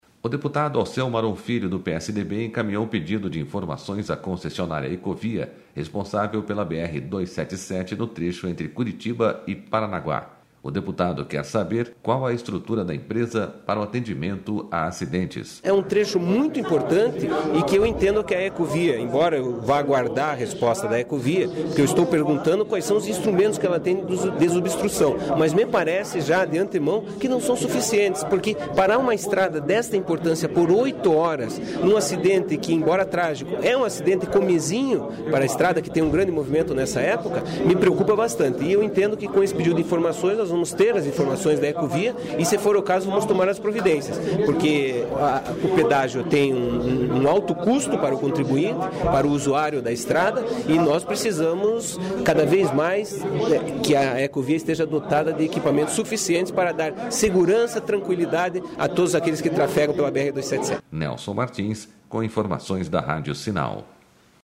SONORA MARON